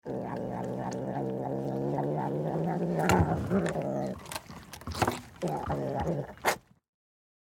Сердитая кошка злобно уплетает свою еду